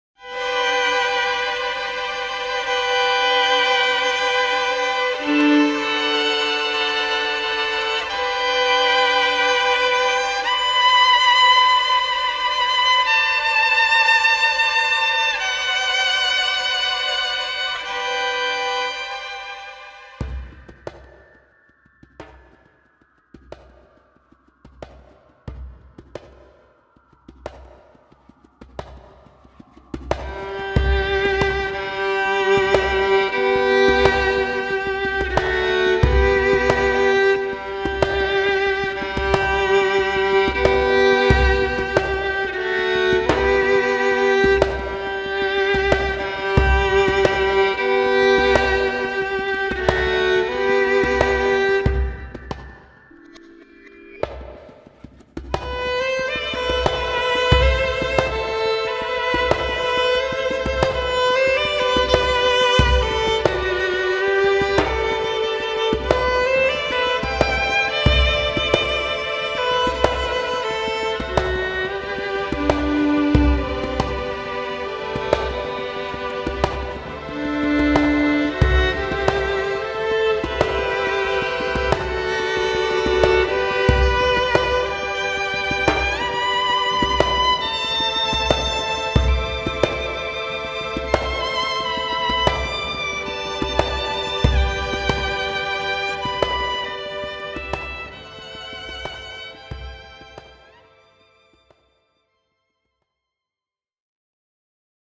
sonata for violin and organ